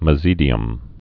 (mə-zēdē-əm)